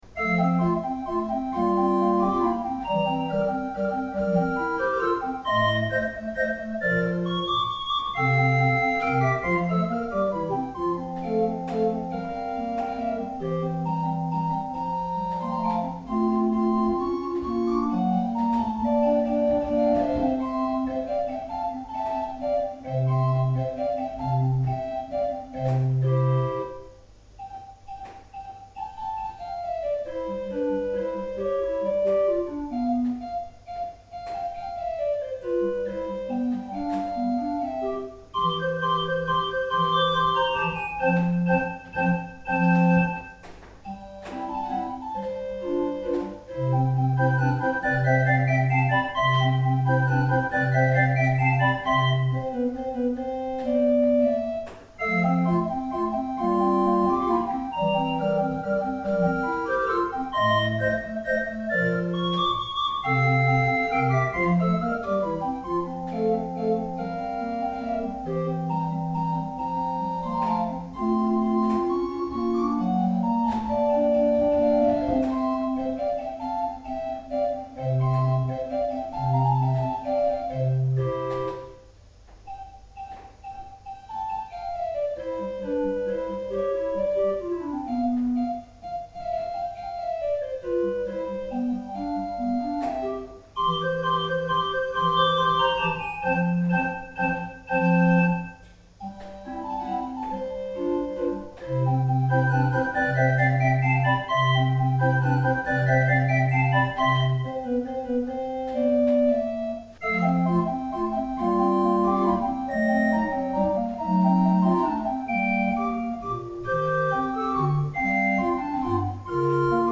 The casework and stop knobs are based on an 18th century English style as is the general sound of the instrument.